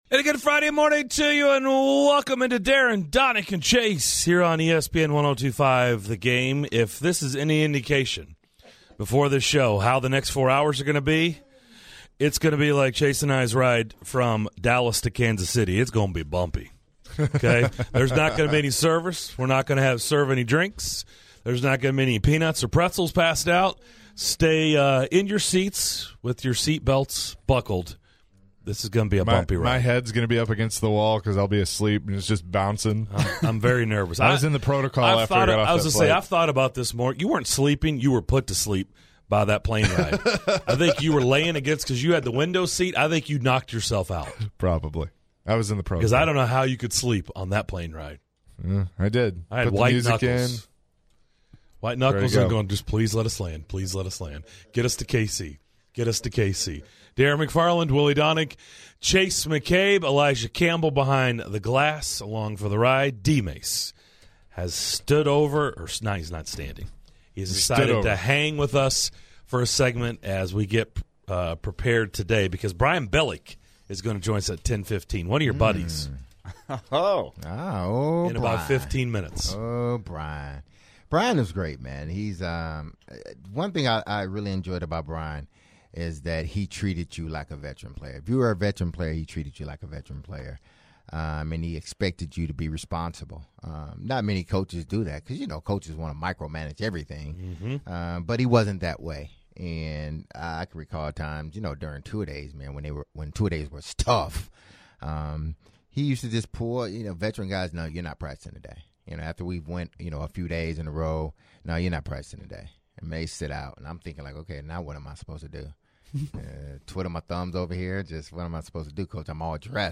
In the opening hour of today's show, the guys preview the Titans - Ravens matchup with former NFL HC Brian Billick and former Titans/Ravens WR Derrick Mason.